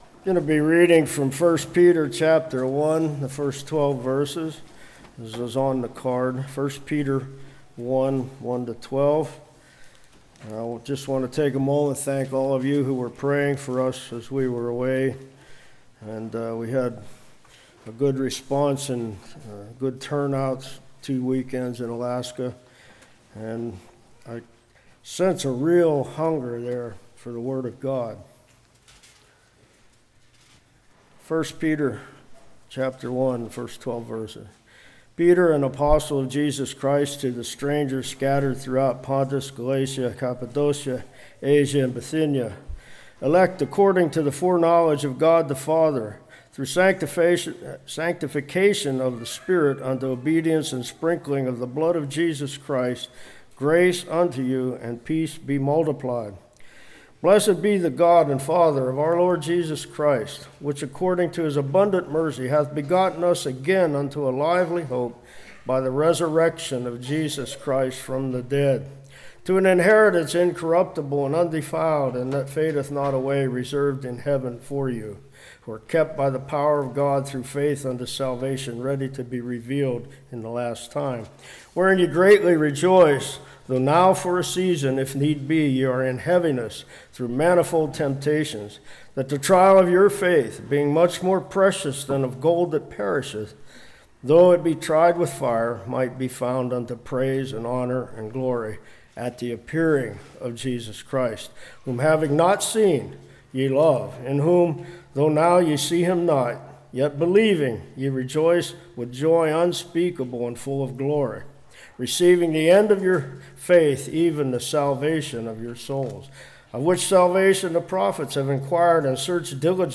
1 Peter 1:1-12 Service Type: Revival Author Setting Audience How was Peter qualified to write his epistles?